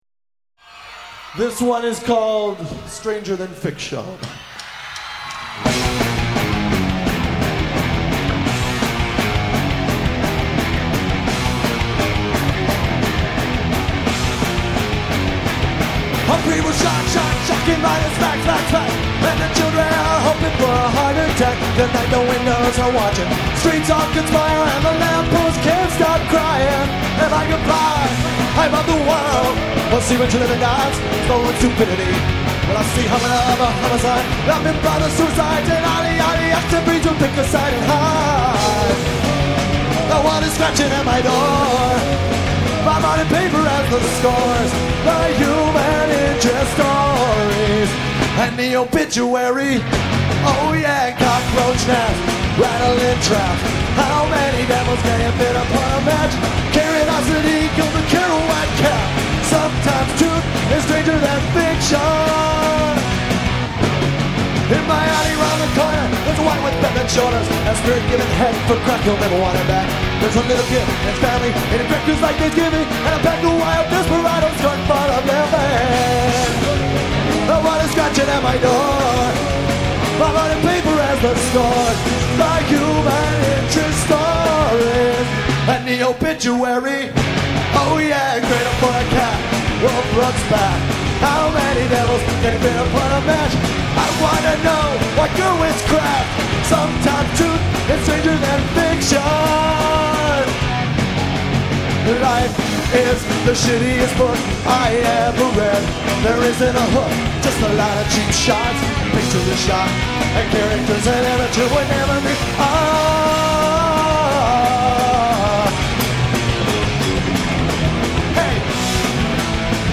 Tracks 2,3,6 and 7: live from KROQ Acoustic Xmas line